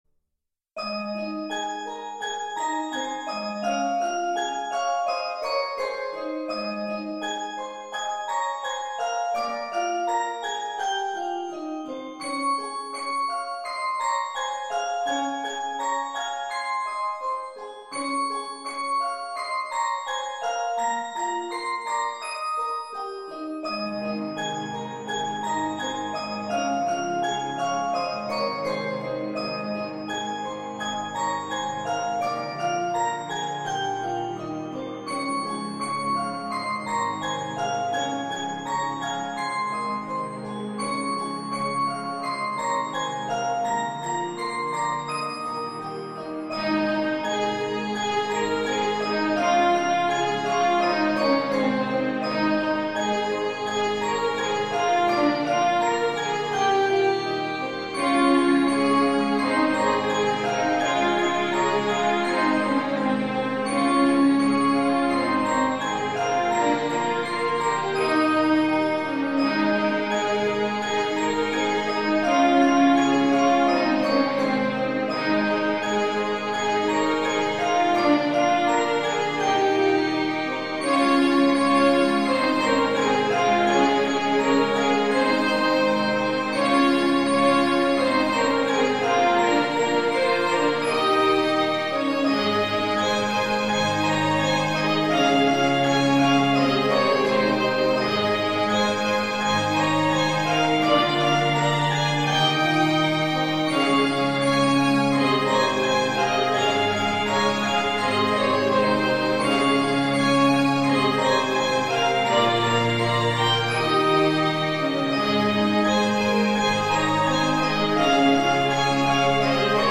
Orchestral Cover